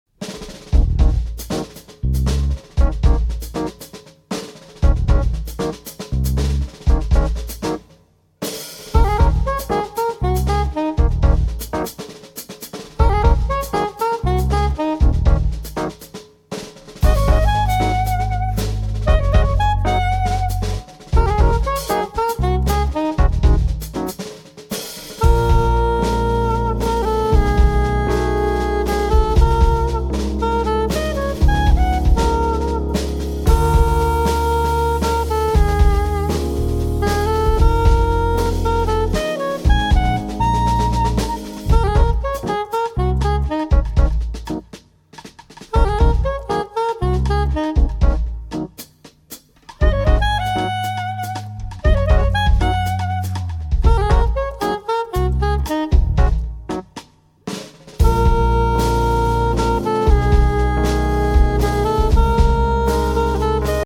sassofoni tenore e soprano
piano e tastiere
basso elettrico
batteria